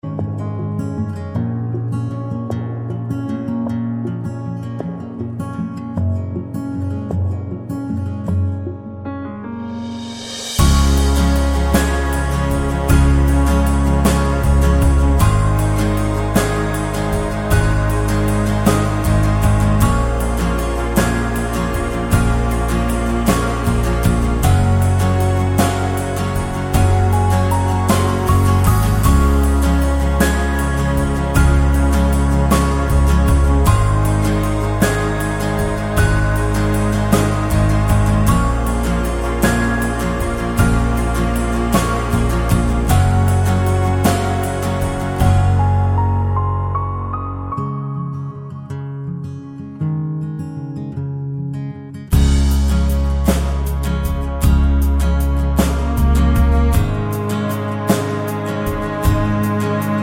no Backing Vocals Pop (2010s) 3:38 Buy £1.50